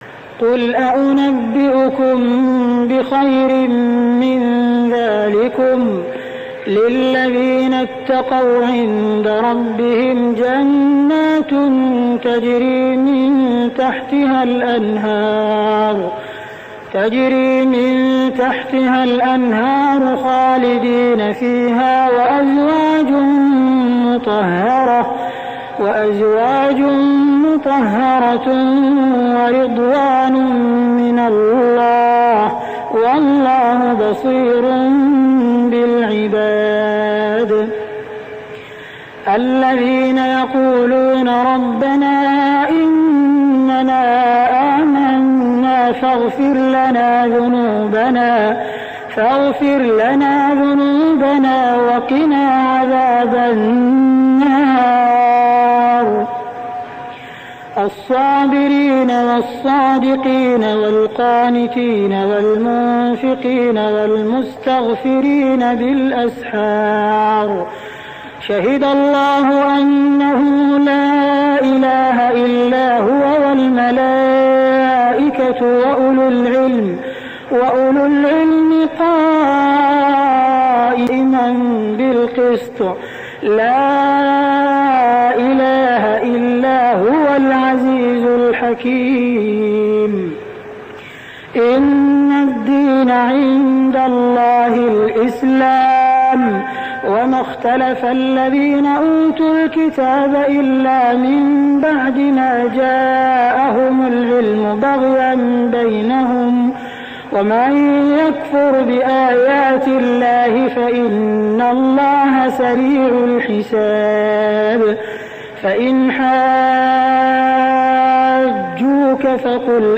صلاة التراويح ليلة 3-9-1409هـ سورة آل عمران 15-92 | Tarawih prayer Surah Al-Imran > تراويح الحرم المكي عام 1409 🕋 > التراويح - تلاوات الحرمين